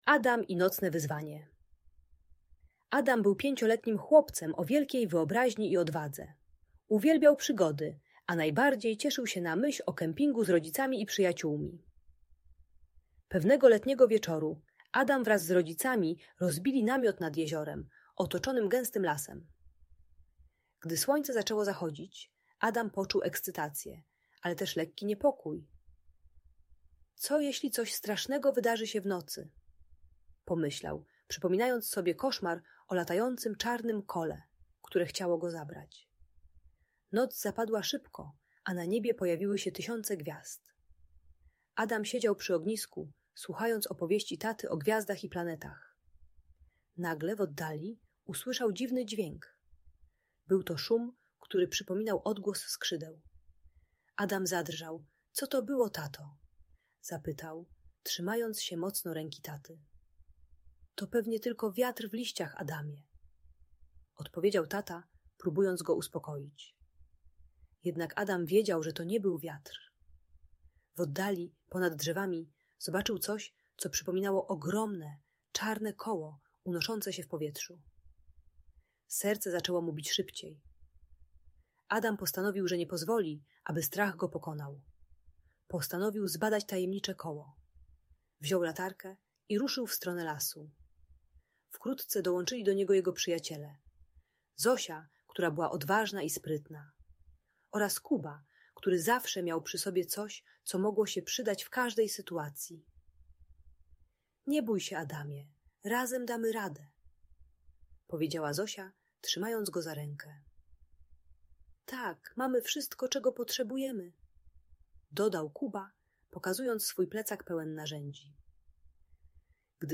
Opowieść o Adamie i Nocnym Wyzwanie - Audiobajka